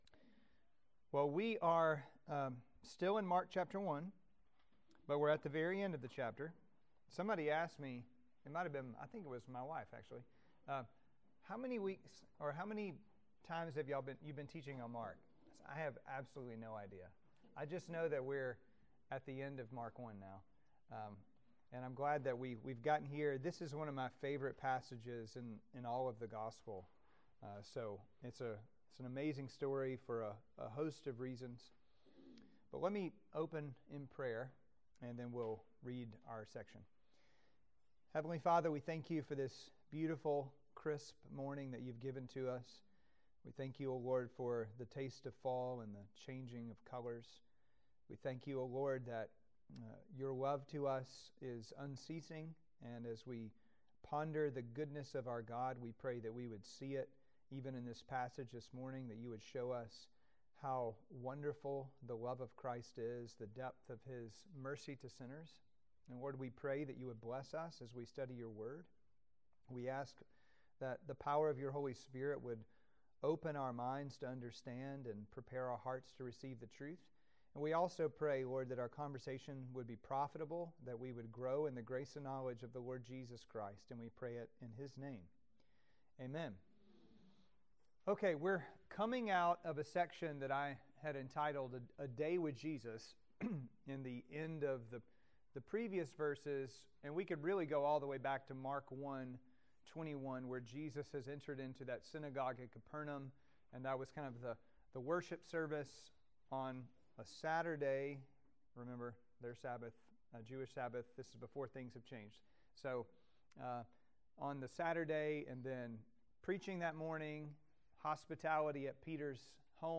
10. Mark 1:40-45 Sermons & Sunday School Series podcast